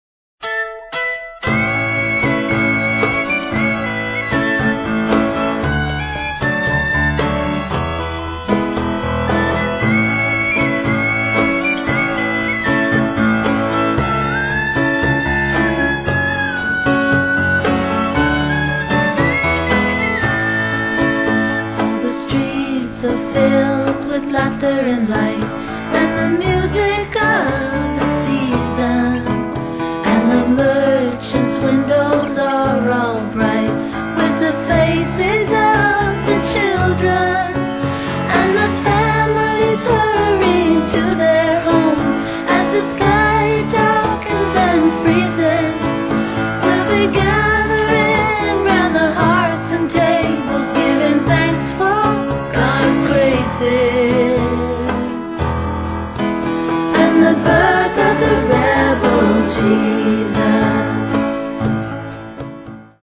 Noget så specielt som en skramlet gammeldags folk-juleplade.